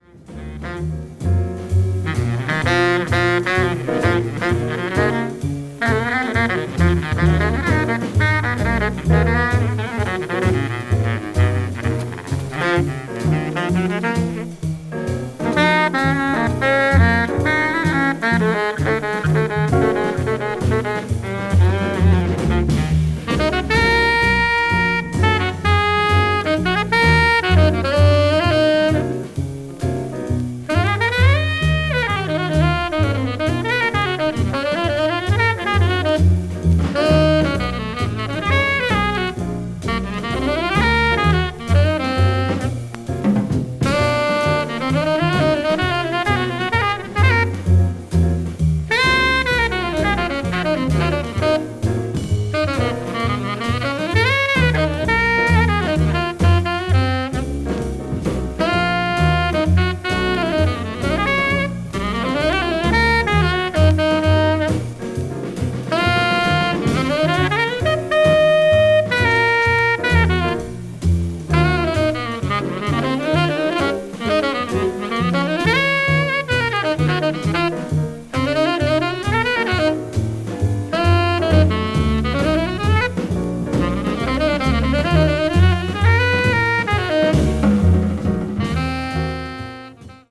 hard bop   modal jazz   modern jazz